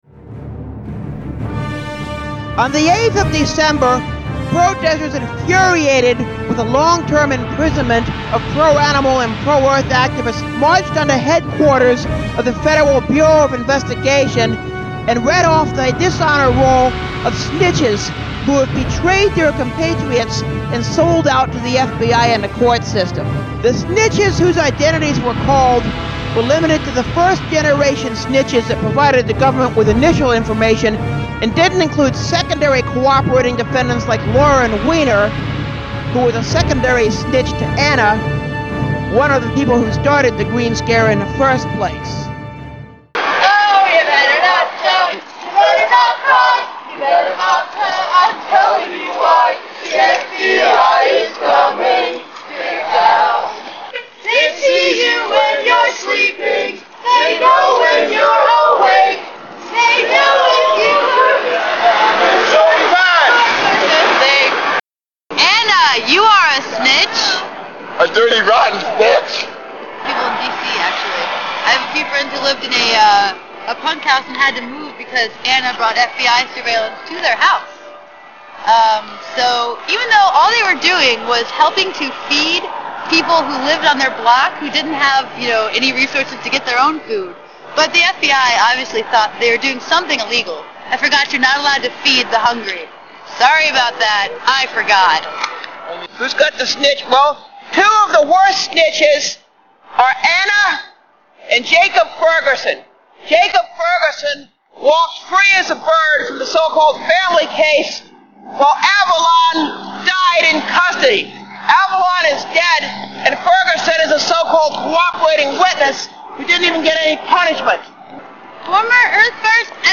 A song sung during the protest goes like this:
This is the WSQT Guerrilla Radio coverage of the protest
greenscare_protest.mp3